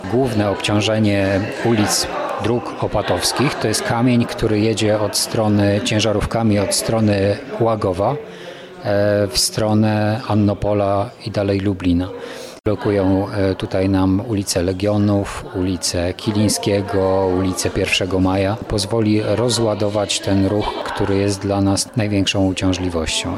Burmistrz Grzegorz Gajewski podkreśla, że inwestycja jest konieczna, aby miasto mogło normalnie funkcjonować.